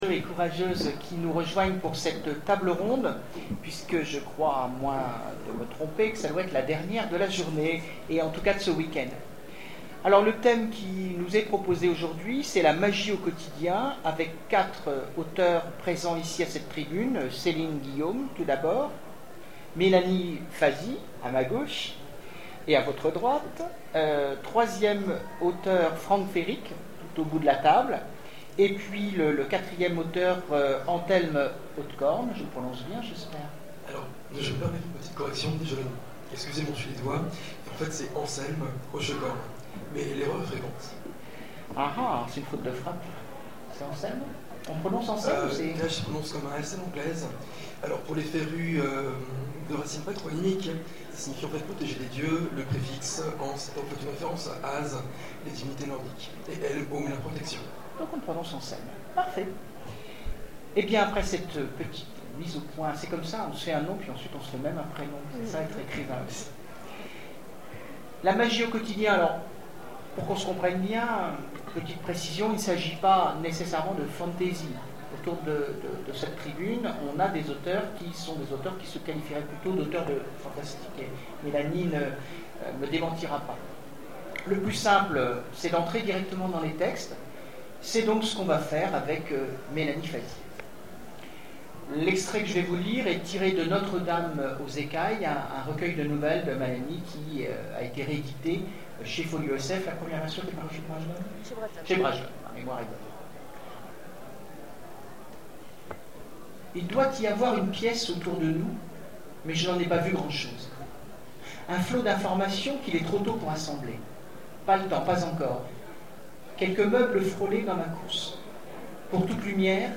Zone Franche 2013 : Conférence La magie au quotidien